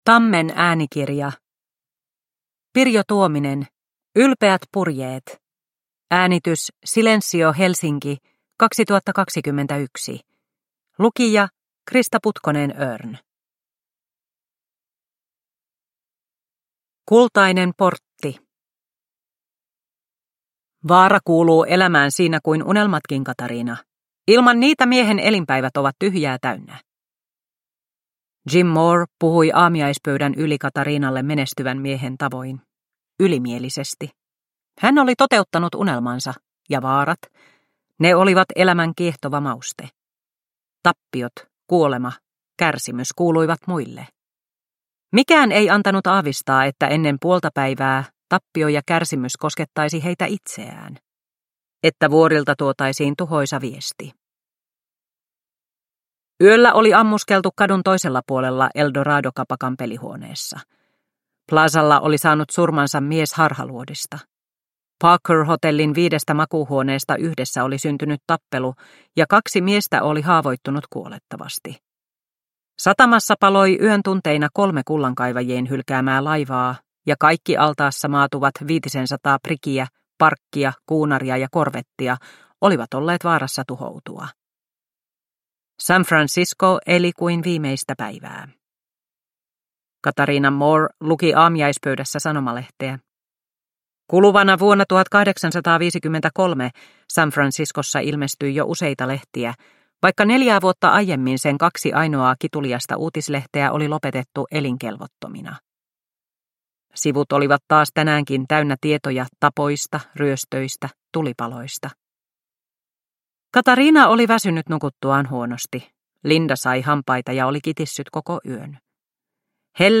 Ylpeät purjeet – Ljudbok